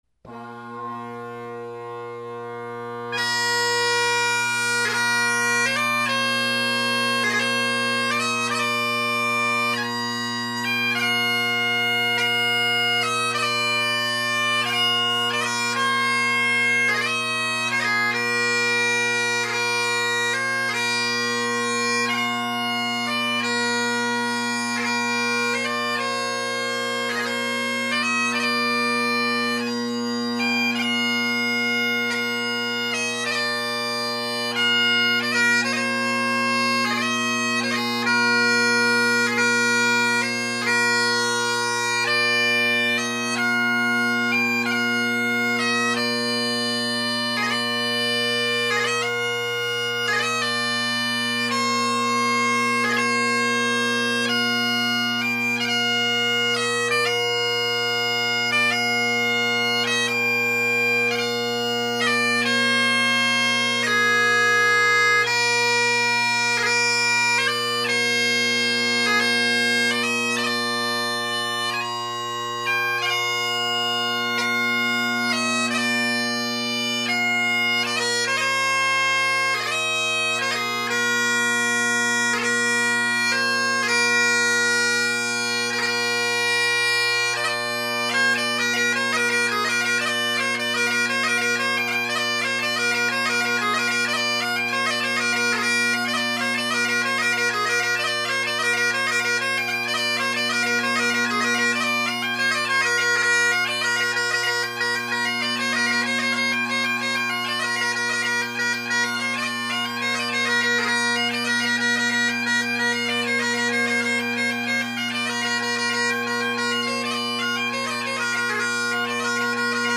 Robertson bagpipes with Robertson Rocket drone reeds
Drone Sounds of the GHB
The bass is big and the tenors mellow and with where I put the recorder (same spot as usual) you get a lot of bass without much tenor. There are spots in the recordings where you can hear the blend as for the most part I’m usually just meandering around the room, so they’re there, you’ll just have to wait for them.